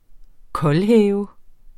Udtale [ ˈkʌlˌhεːvə ]